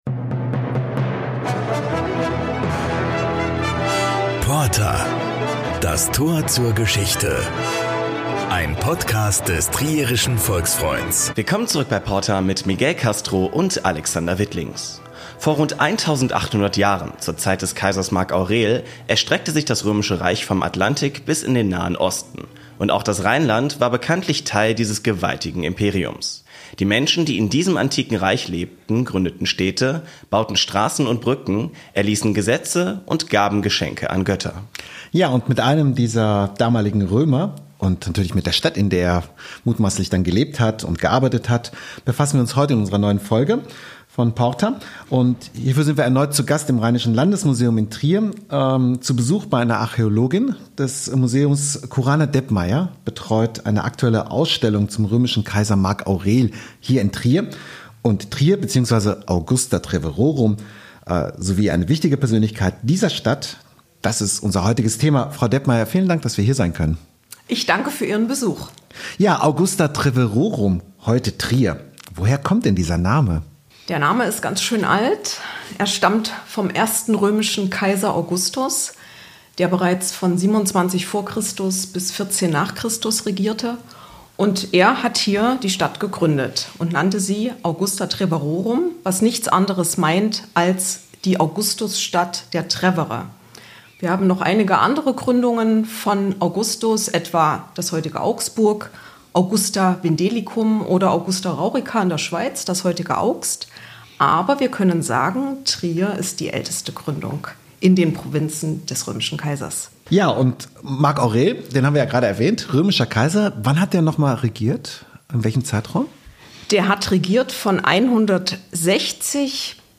Und wie lebten die Menschen seiner Zeit im damaligen Trier, zur Zeit der Herrschaft des Kaisers Marc Aurel? Eine Spurensuche mit der Archäologin